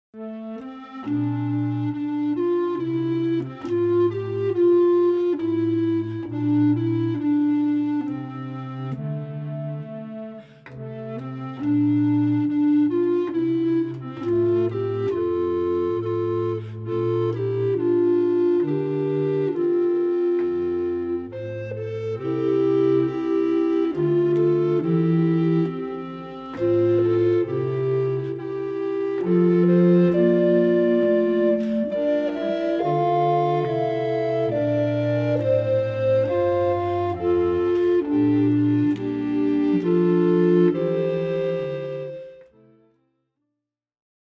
リコーダー編曲集